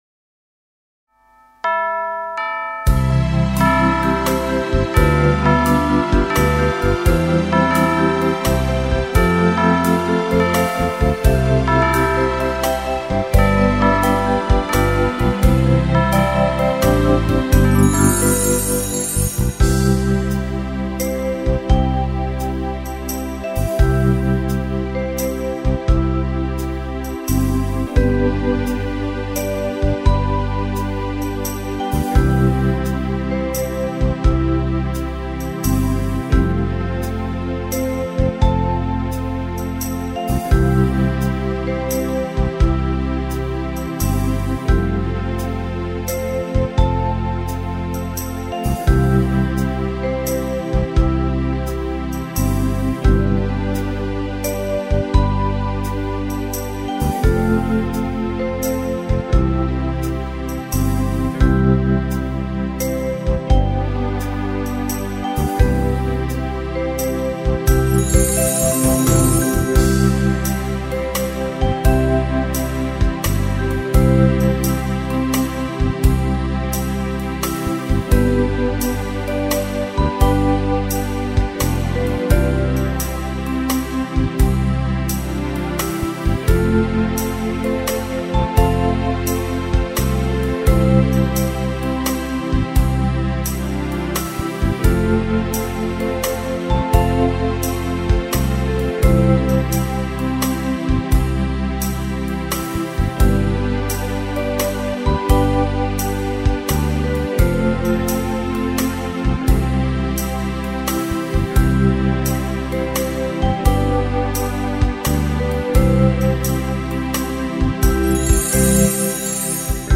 Świeczki na choince – zabawa rytmiczna Zabawy dla dzieci.